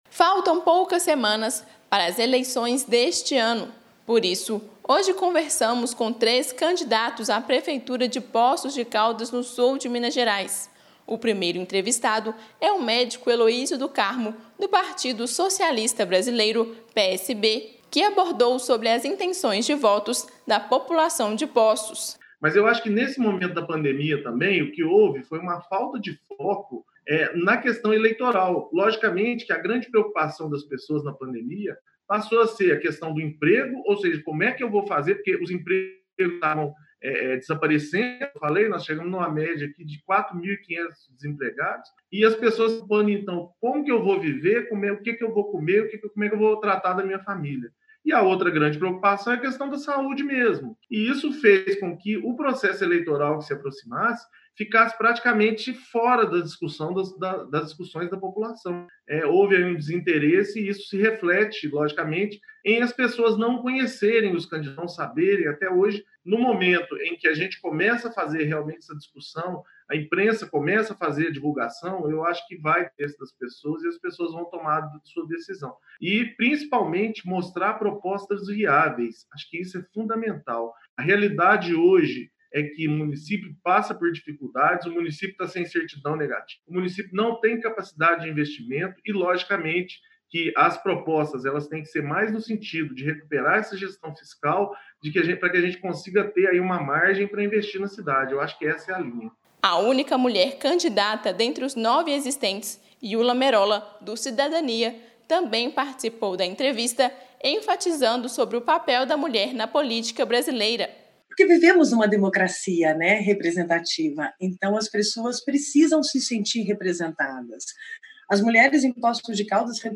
AMIRT Destaque Esporte Minas Gerais Notícias em áudio PolíticaThe estimated reading time is 2 minutes
O Portal Amirt realizou entrevistas exclusivas com dezenas de candidatos à prefeitura de cidades mineiras